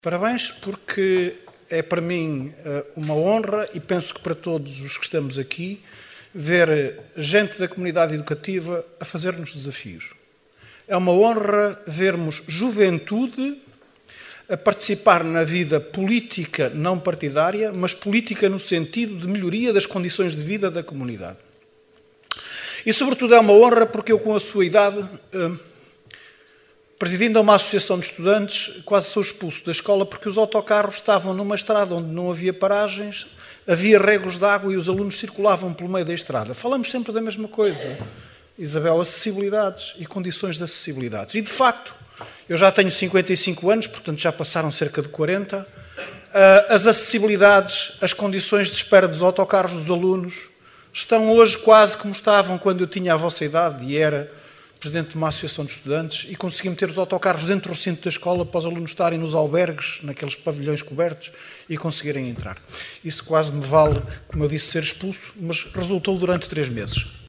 Excertos da última assembleia municipal, realizada a 16 de Dezembro no Teatro Valadares em Caminha.